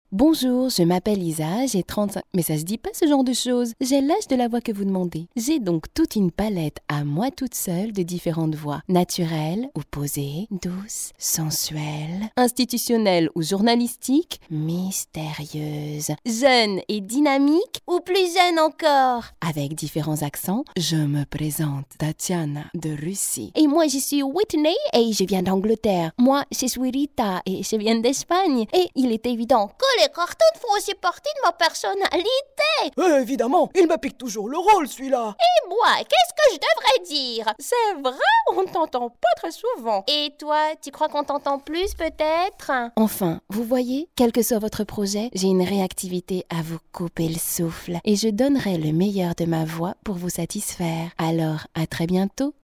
Mon timbre est dans les aigus léger, un ton naturel ou posé, une voix jeune, douce ou tonique, sensuelle ou journalistique, sérieuse ou séductrice, avec différents accents ou encore de cartoons!
Sprechprobe: Werbung (Muttersprache):